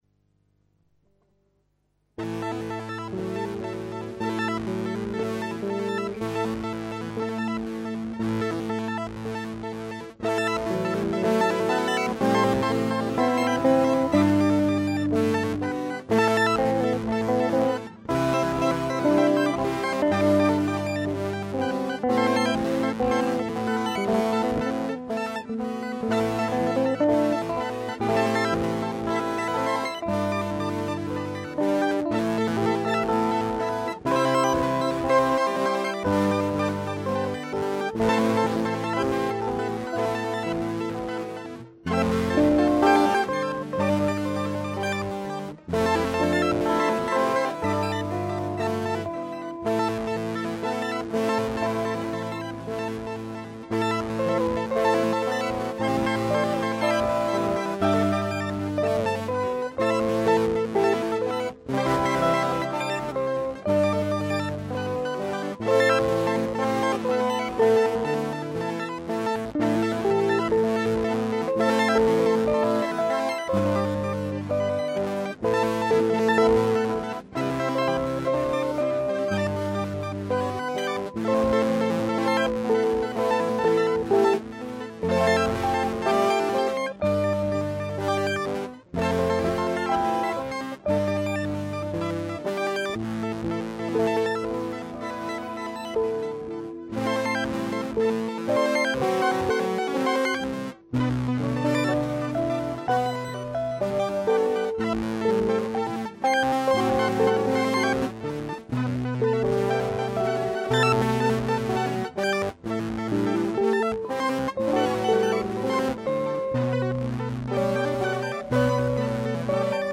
2 Gitarren